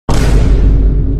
Memes
Discord Boom